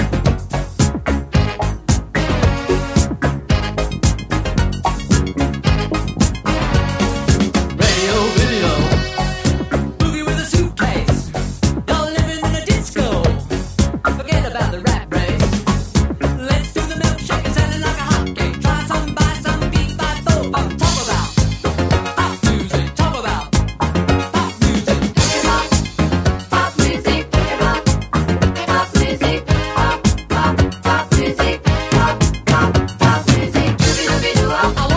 1979-01-01 Жанр: Поп музыка Длительность